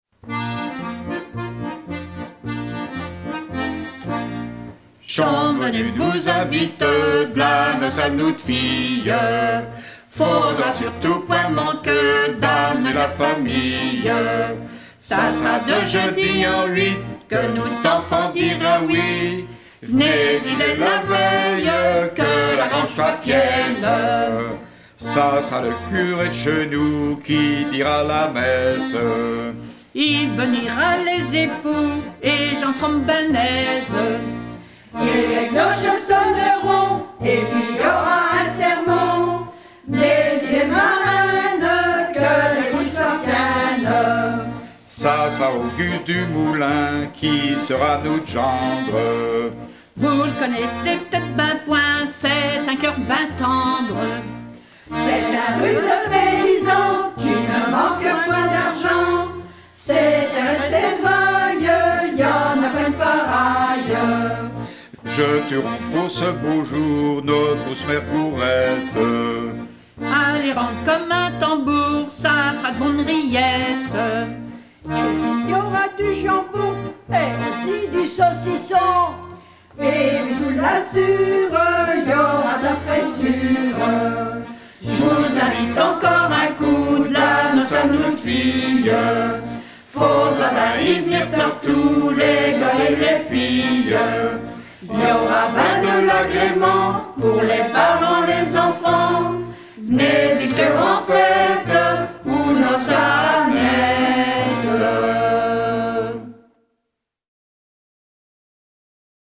La noce à Annette – Ce chant est extrait du CD Treillières à travers chants, publié en 2019 par l’association Treillières au fil du temps (TAFDT). Support d’un patrimoine immatériel de culture populaire et rural, le CD entend être le témoignage de la tradition orale, qui se perpétue par toutes petites touches lors de fêtes familiales encore « chanteuses » et lors de fêtes à caractère culturel.